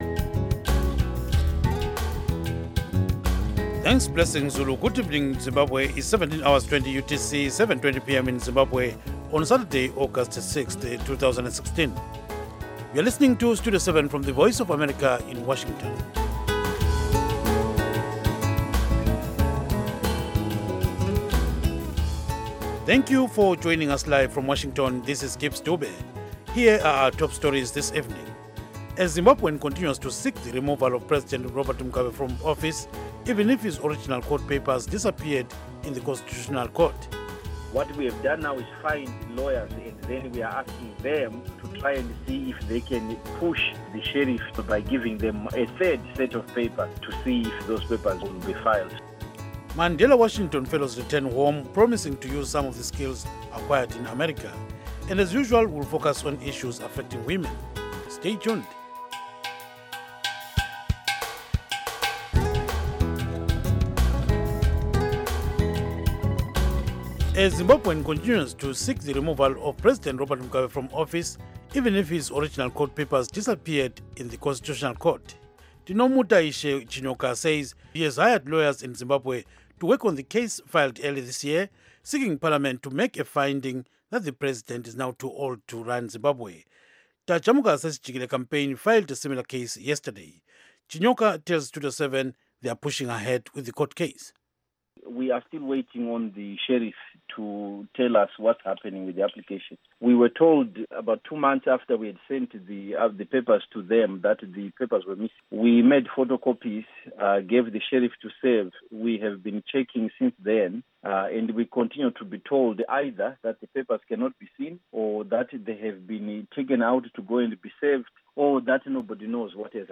Studio 7 News in English